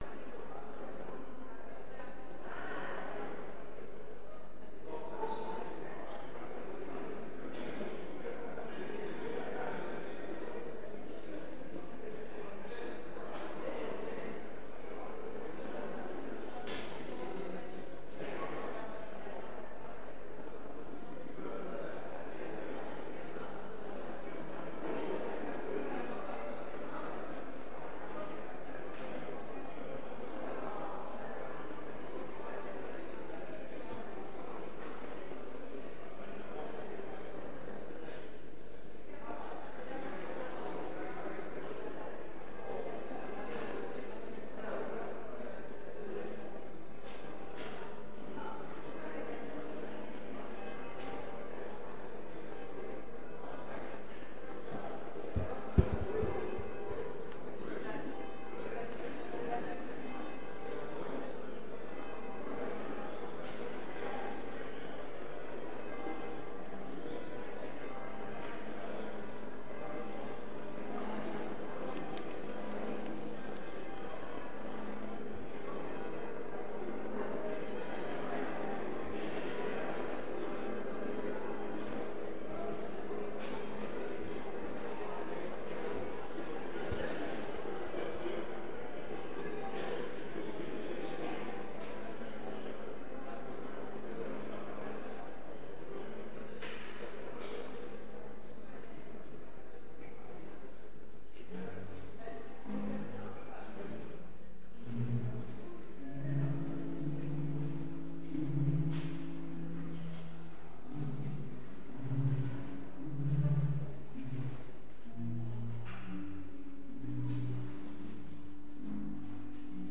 Culte du 22 décembre 2024
Orgue